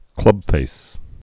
(klŭbfās)